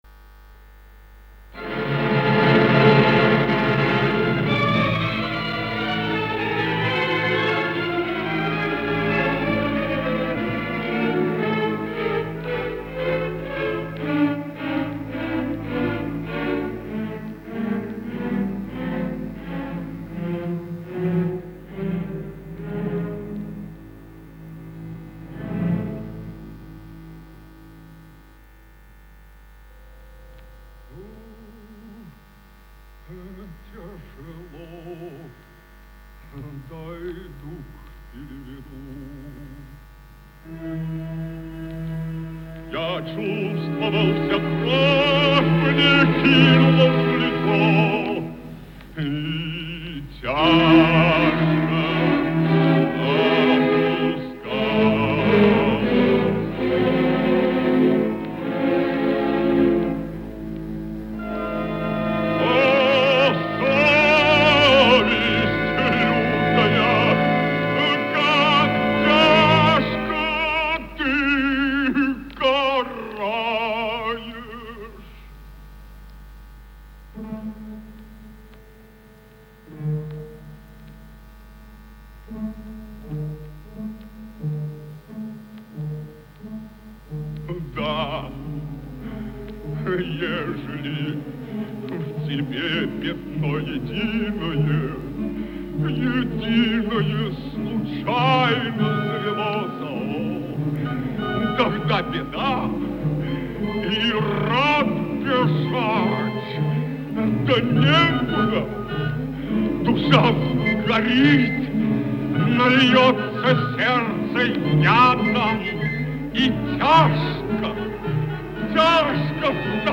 Звуковая страница 5 - Поёт Ф.И.Шаляпин. М.Мусоргский. 'Сцена с курантами' из оперы 'Борис Годунов'. Вы слышите впервые: А.Гречанинов - 'Речитатив' (фрагмент "Великой ектеньи").